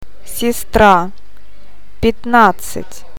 O ääntyy painottomana a :n kaltaisena, e ja я i :n tai ji :n tapaisena äänteenä.